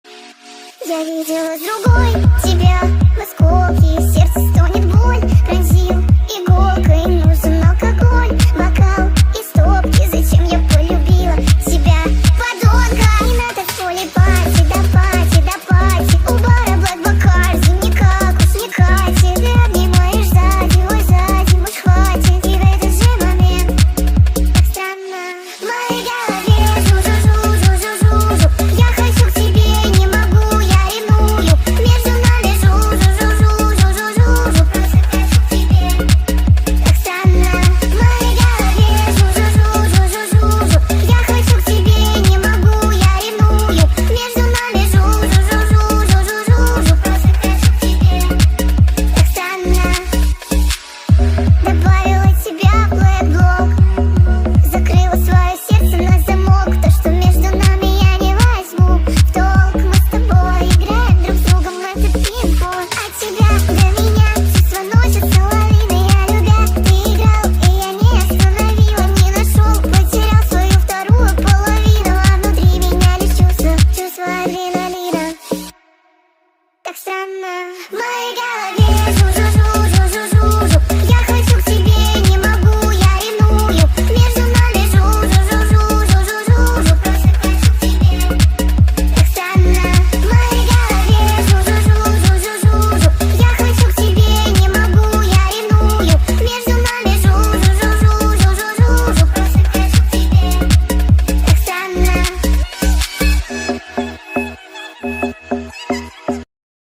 Качество: 320 kbps, stereo
Песня из тик тока Полная Версия 2025 Speed up